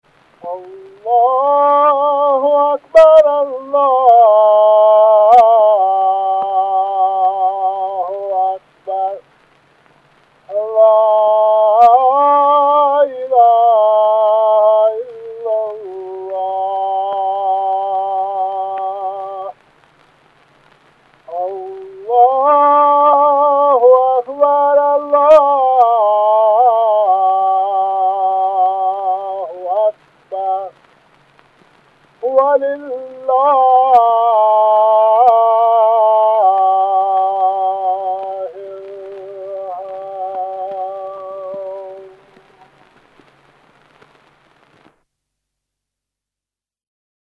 Hazrat Inayat Khan 1915 SufiLab One of Heart 2 Sufi Message .mp3 THE VOICE of HAZRAT INAYAT KHAN - 1 Song of Asif .mp3 2 Sufi Message; 3' text of the speech: Beloved one's of God.
A museum is to take down my voice in the gramophone records to keep for the coming generation.
05 Invocation b.mp3